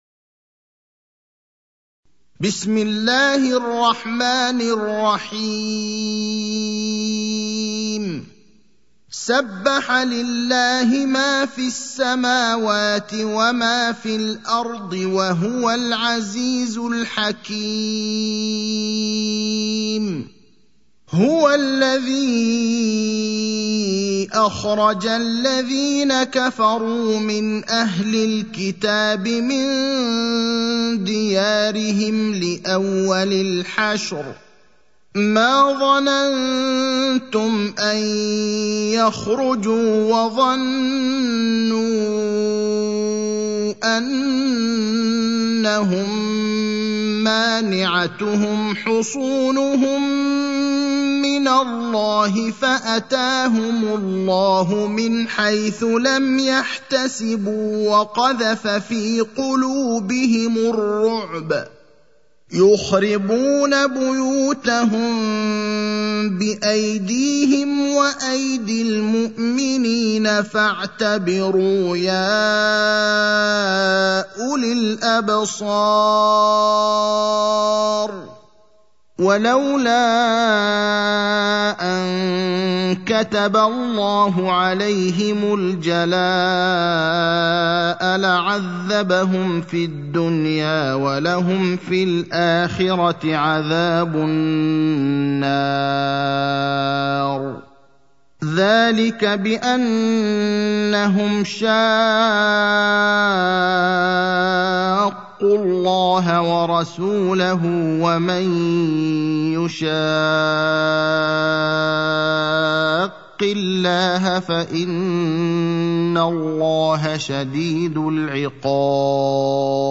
المكان: المسجد النبوي الشيخ: فضيلة الشيخ إبراهيم الأخضر فضيلة الشيخ إبراهيم الأخضر الحشر (59)